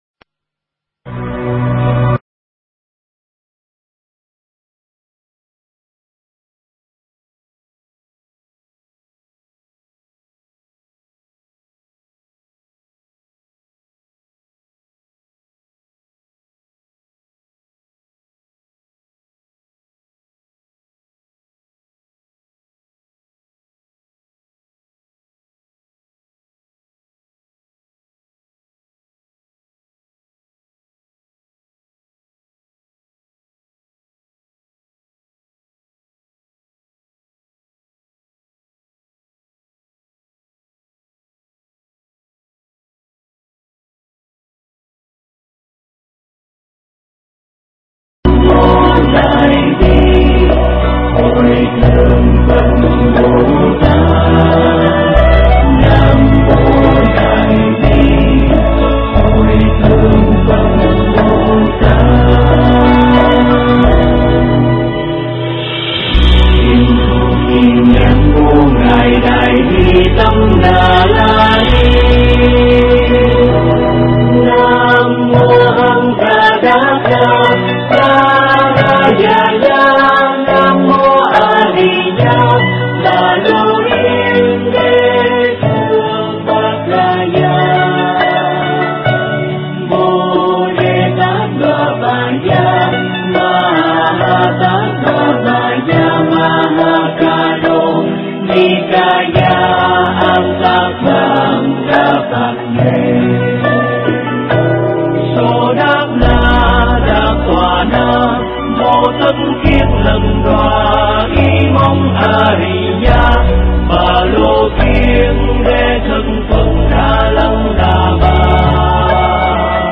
thuyết giảng tại Tu Viện Trúc Lâm (Edmonton, AB, Canada)